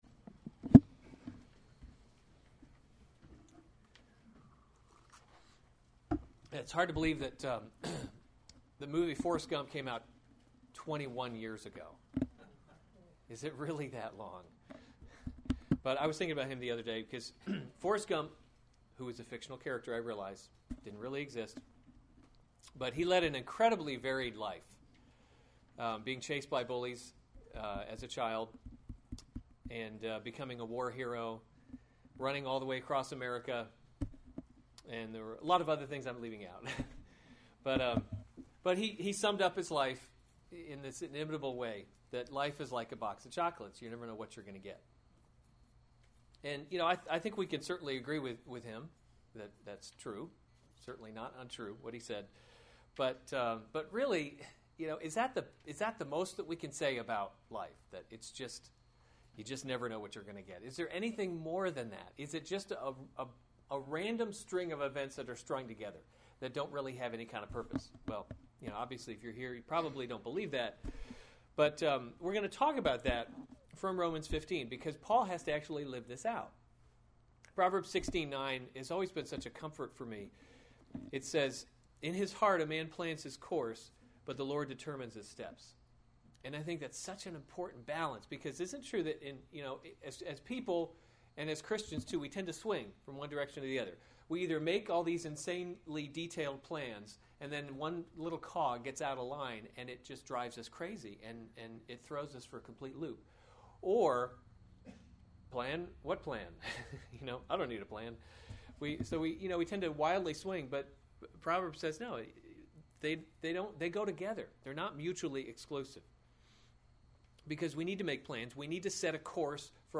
May 16, 2015 Romans – God’s Glory in Salvation series Weekly Sunday Service Save/Download this sermon Romans 15:22-33 Other sermons from Romans Paul’s Plan to Visit Rome 22 This is the […]